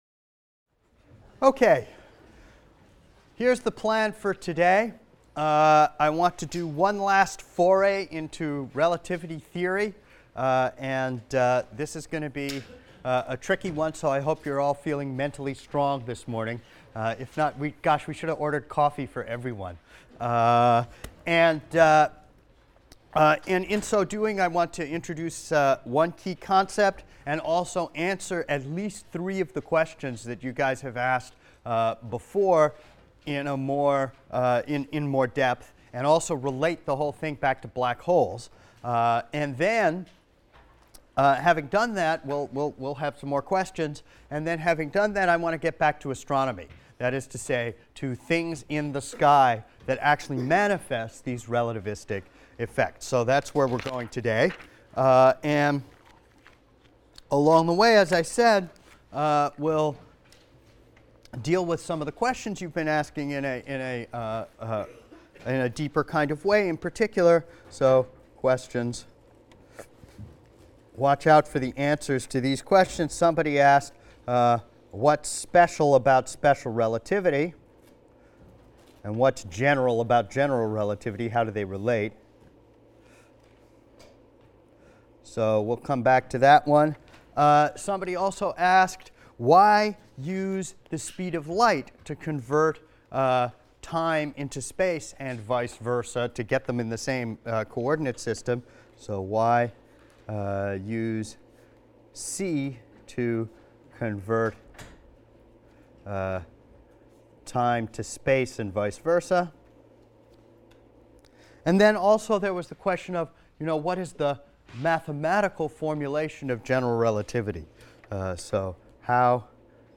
ASTR 160 - Lecture 12 - Stellar Mass Black Holes | Open Yale Courses